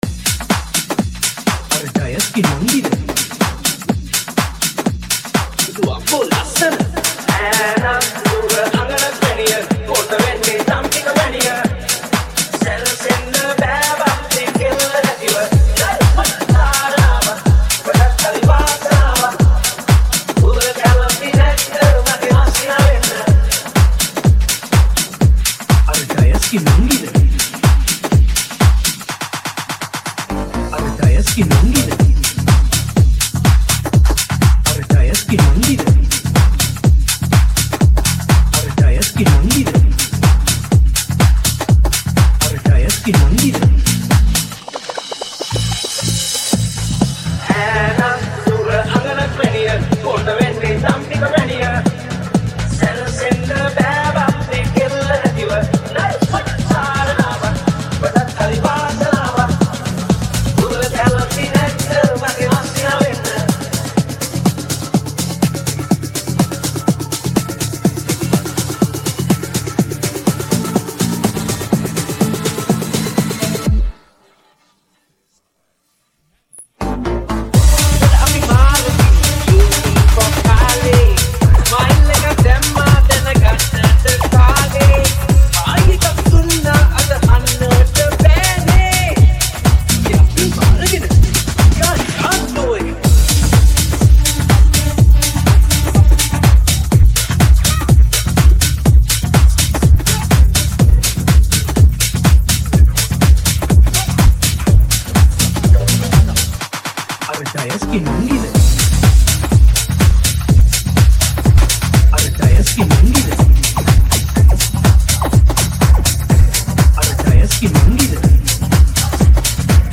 High quality Sri Lankan remix MP3 (4.6).
remix